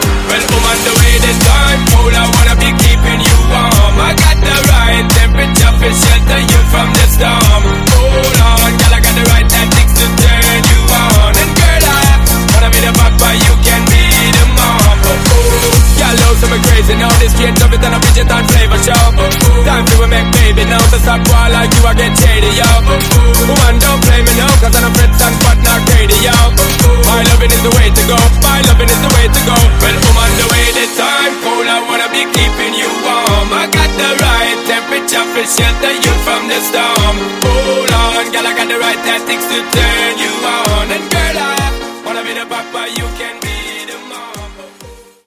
Genres: HIPHOP , TIK TOK HITZ , TOP40
Dirty BPM: 93 Time